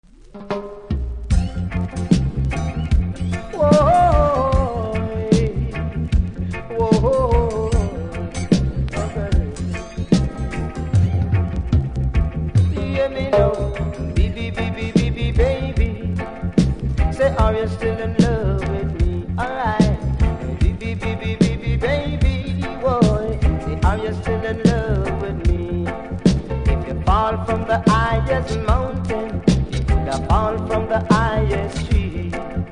うすキズ多々ありますが音は良好なので試聴で確認下さい。